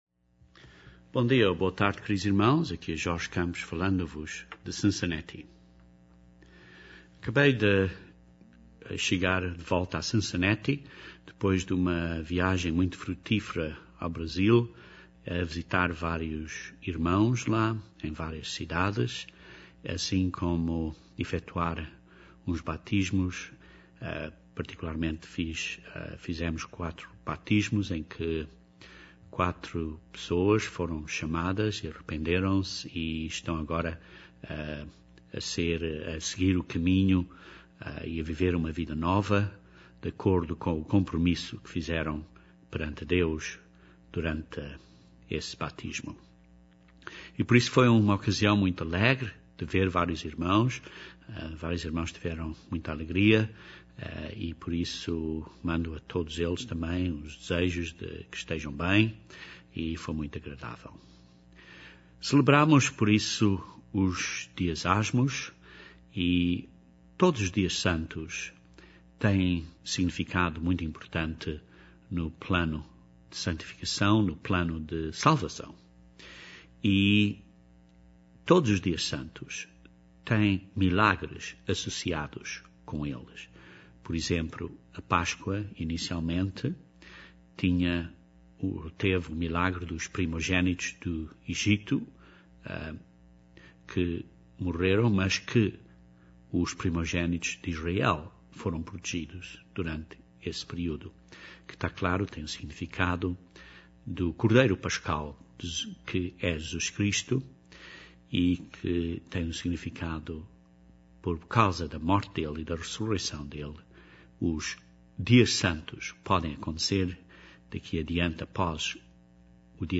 Este sermão descreve este maior milagre de todos, que é o resultado de Jesus Cristo viver em nós, o resultado do que o Espírito Santo de Deus em nós produz.